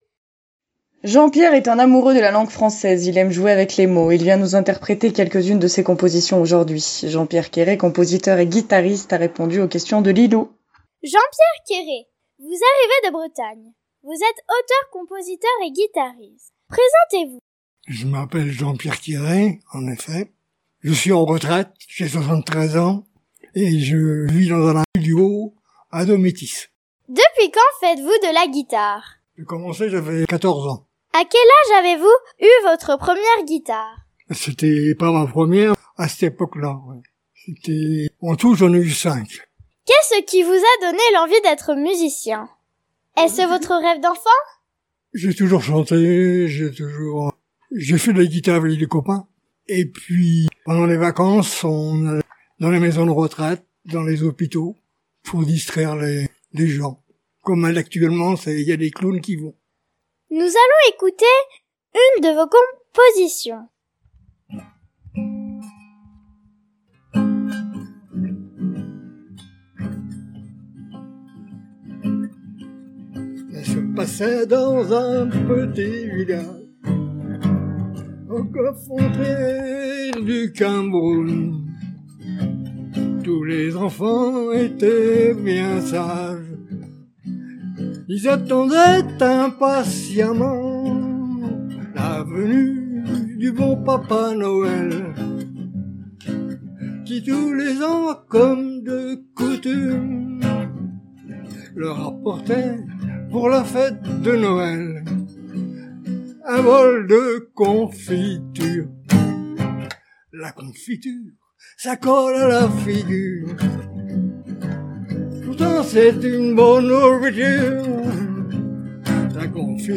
Il vient nous interpréter quelques une de ses compositions aujourd'hui.
compositeur et guitariste